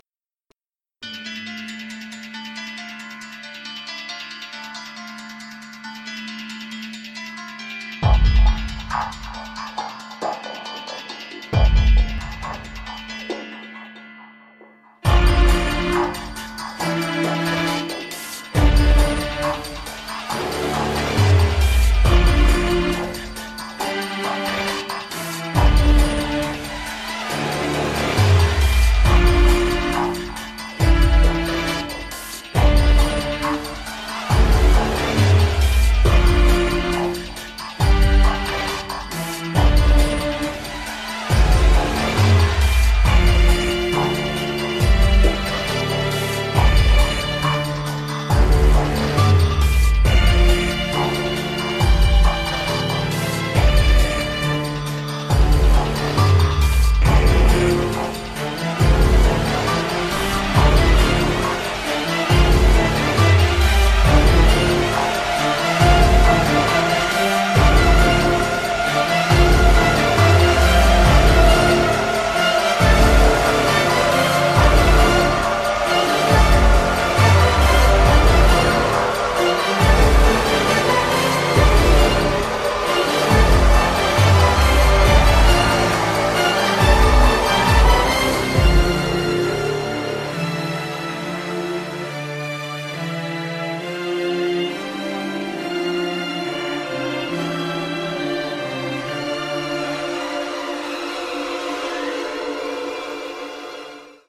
ze_ambience_saw.mp3